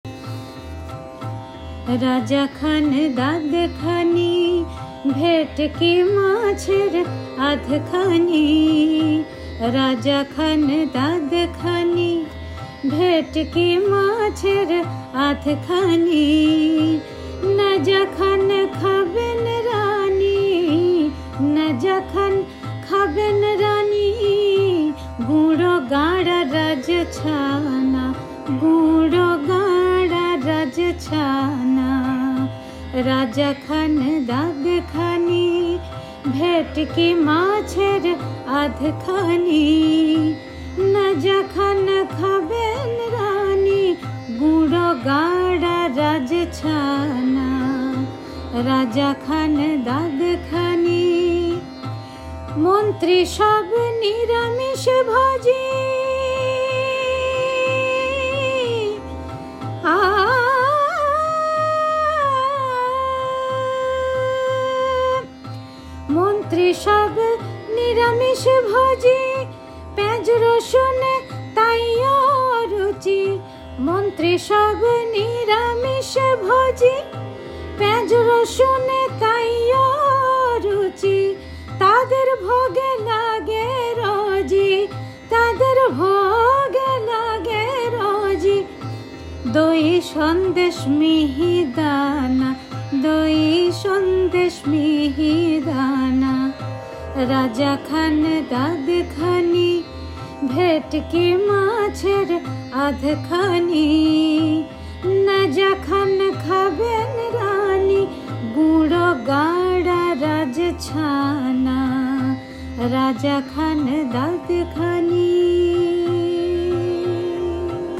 ভৈরবী রাগে হালকা চালে বাঁধা গান, কিন্তু বড় গভীর কথা বলে।
বৈঠকি এই হাসির গানটির প্রথমাংশ গেয়ে শোনালাম।